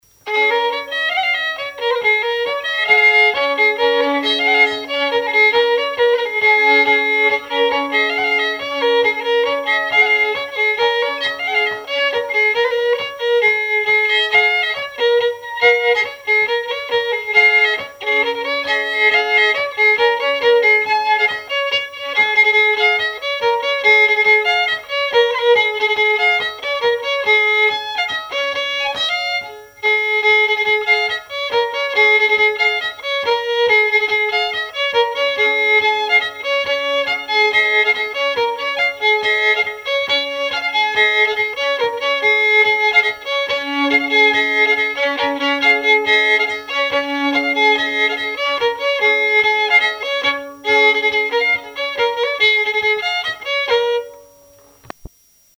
répertoire de chansons et témoignages
Pièce musicale inédite